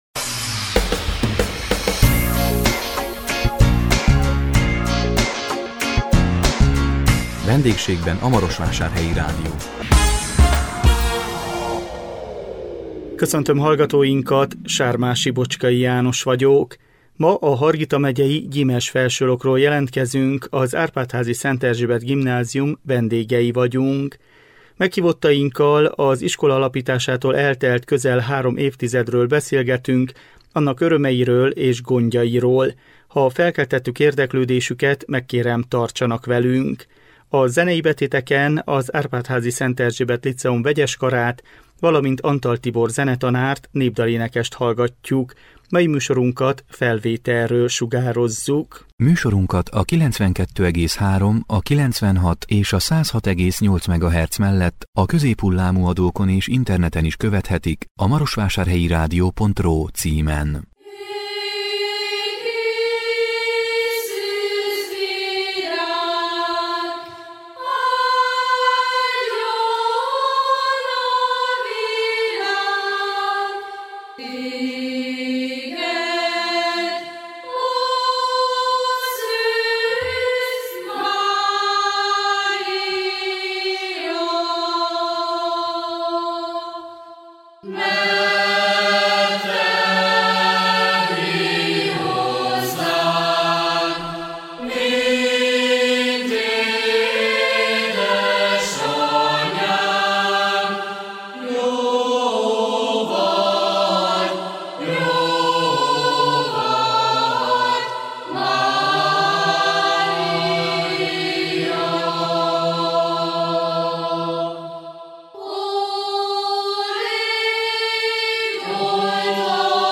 A 2021 május 6-án jelentkező VENDÉGSÉGBEN A MAROSVÁSÁRHELYI RÁDIÓ című műsorunkban a Hargita megyei Gyimesfelsőlokról jelentkeztünk, az Árpádházi Szent Erzsébet Gimnázium vendégei voltunk. Meghívottainkkal az iskola alapításától eltelt közel három évtizedről beszélgettünk, annak örömeiről és gondjairól.